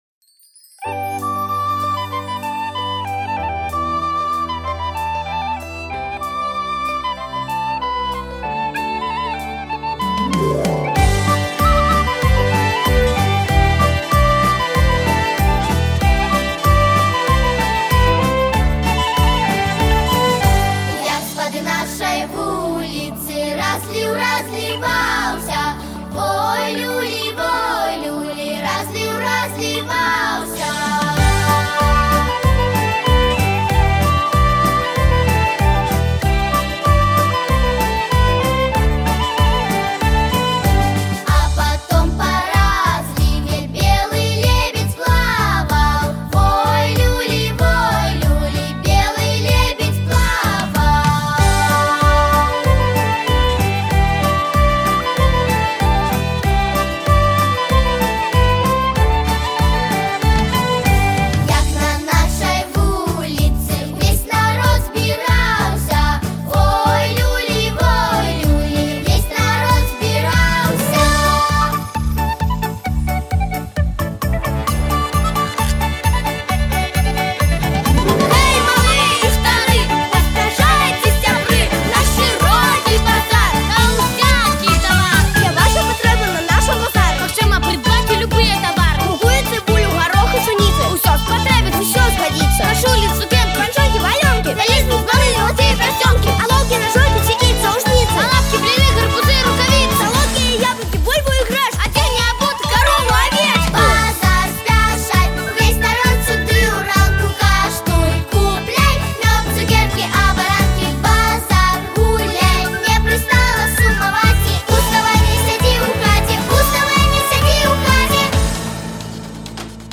• Категория: Детские песни
попурри из бел. нар. песен